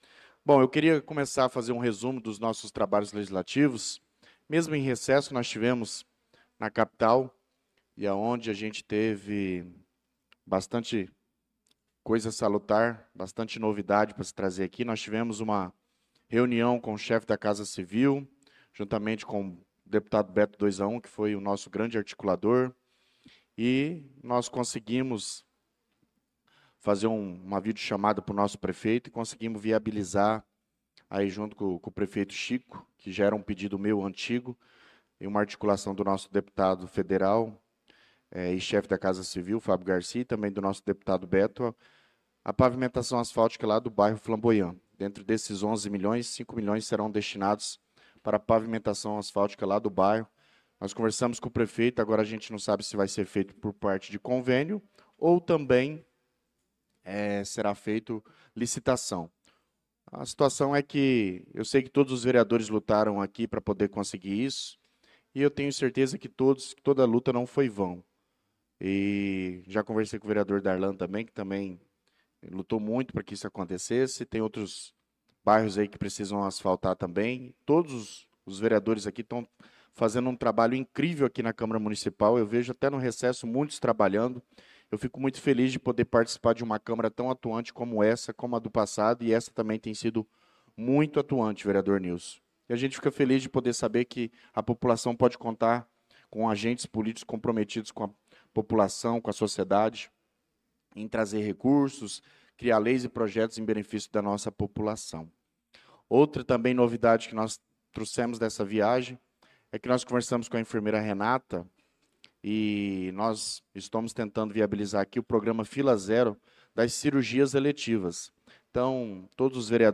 Pronunciamento do vereador Douglas Teixeira na Sessão Ordinária do dia 04/08/2025.